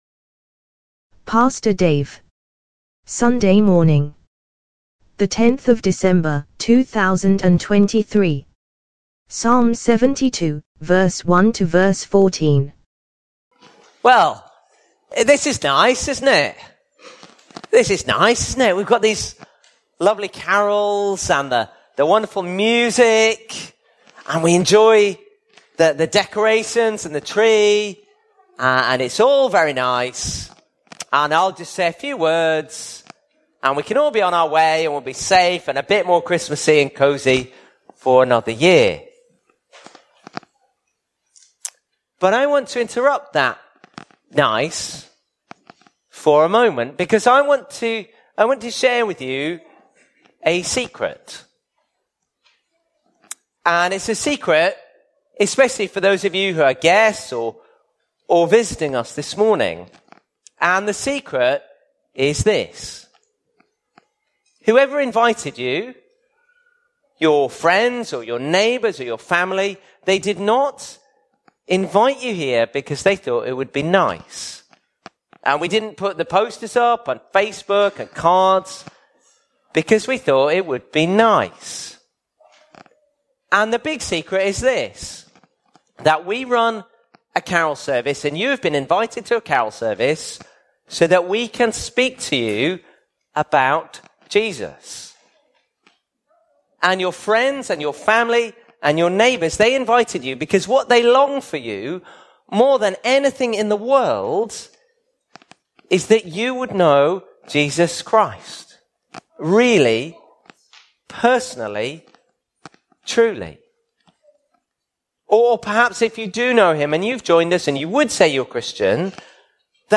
Carol Service Message 2023 MP3 SUBSCRIBE on iTunes(Podcast
Sermons